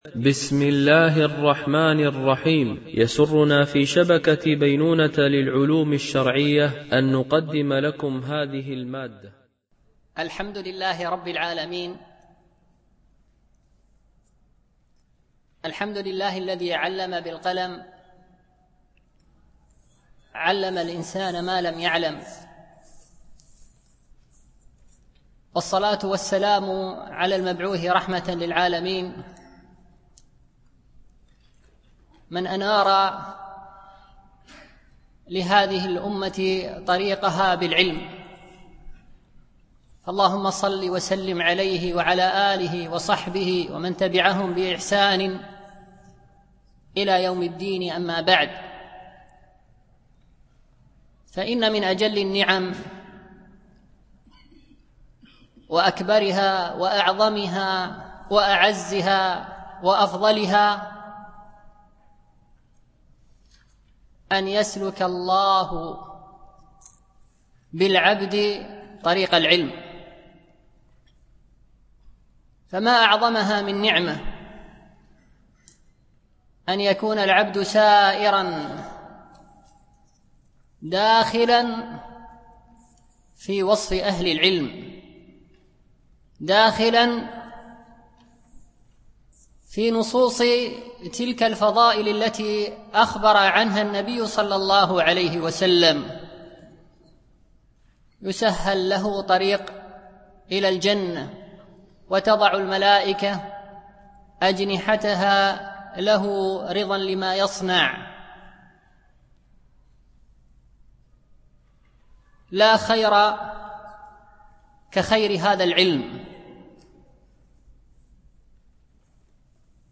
الدرس
دورة الإمام مالك العلمية السادسة، بدبي